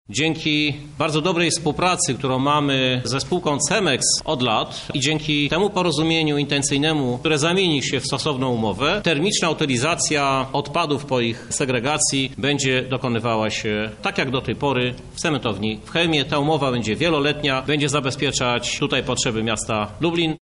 To zamyka wszelkie obawy o ulokowanie spalarni odpadów w Lublinie – mówi Krzysztof Żuk, prezydent miasta